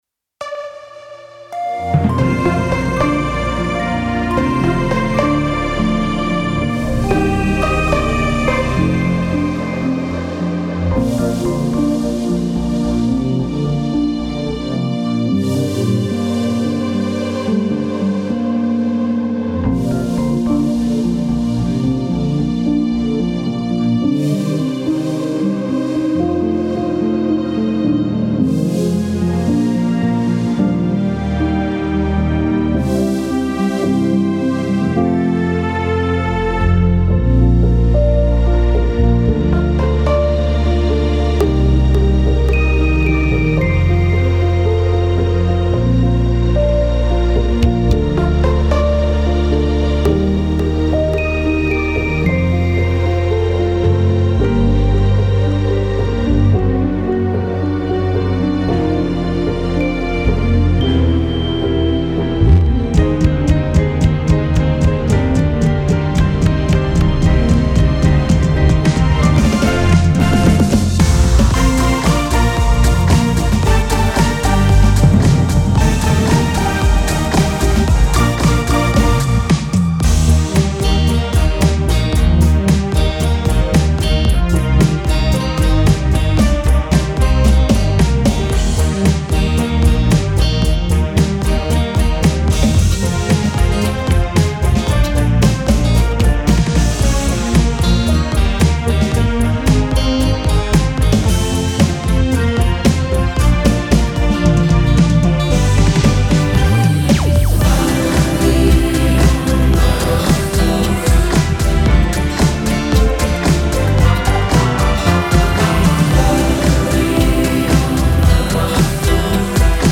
Laulu fono, äkki keegi lauljatest teeb selle ära